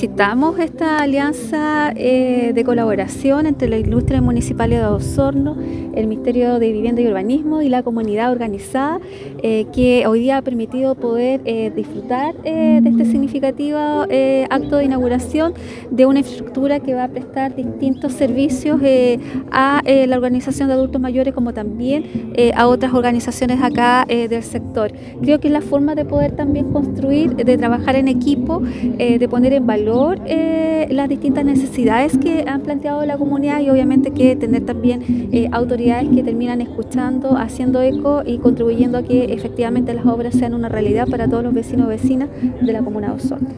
La ceremonia inaugural contó con la participación de diversas autoridades locales, entre ellas los concejales María Soledad Uribe, Juan Carlos Velásquez, Cecilia Canales y Miguel Arredondo, también la Delegada Presidencial Provincial, Claudia Pailalef, quien valoró el trabajo desarrollado por la comunidad organizada y los servicios públicos relacionados.